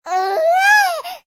babysob2.ogg